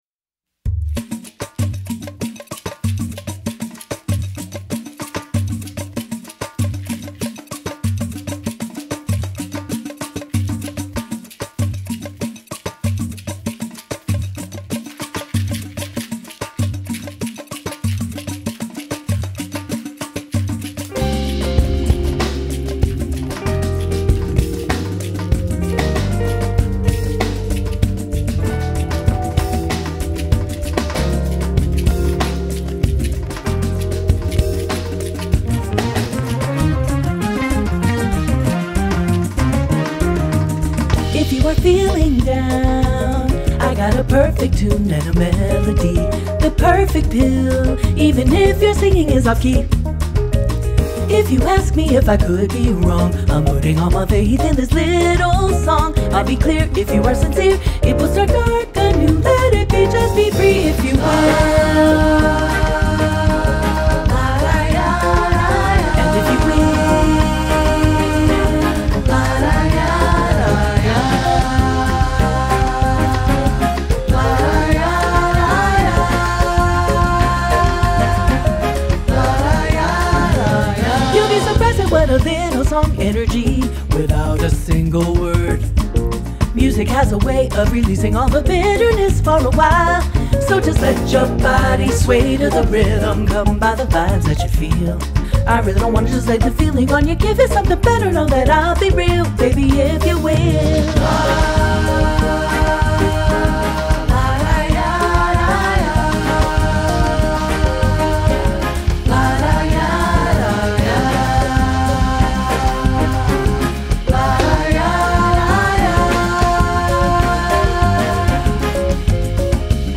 samba jazz